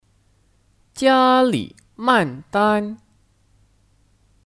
加里曼丹 (Jiālǐmàndān 加里曼丹)